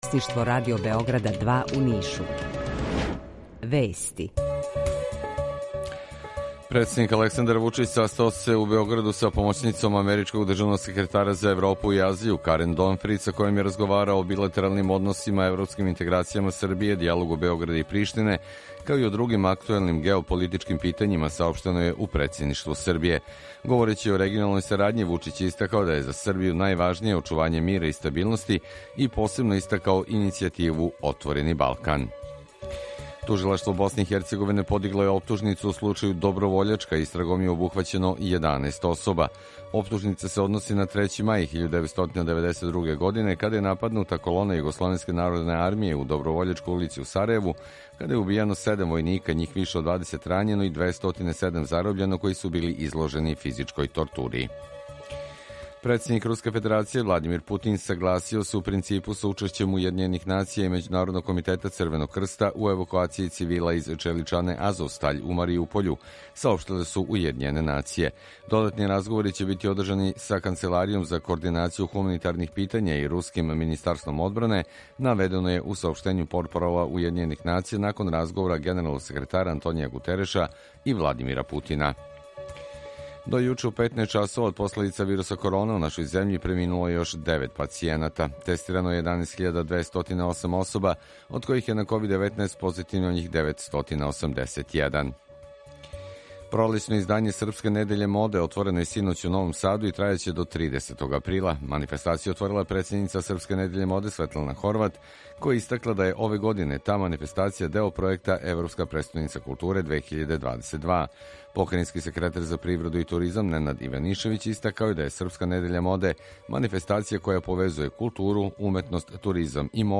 Укључење Kосовске Митровице
Јутарњи програм из три студија
У два сата, ту је и добра музика, другачија у односу на остале радио-станице.